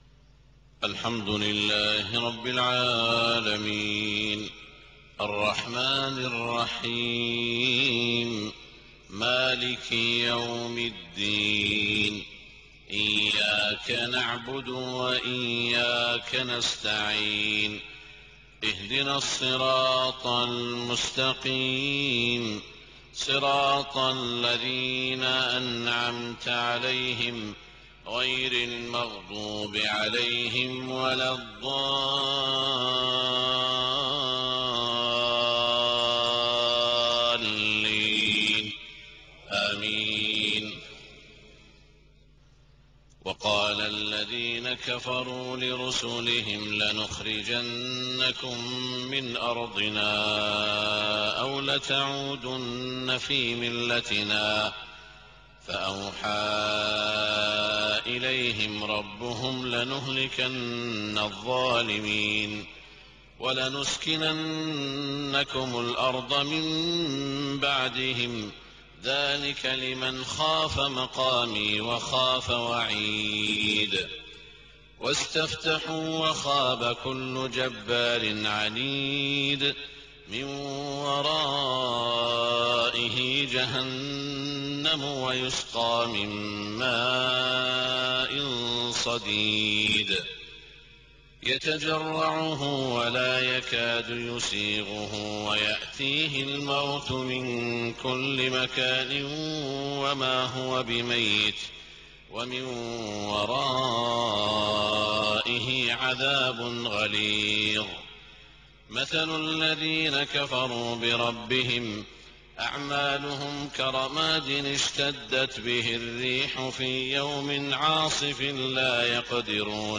صلاة الفجر 24 صفر 1429هـ من سورة إبراهيم > 1429 🕋 > الفروض - تلاوات الحرمين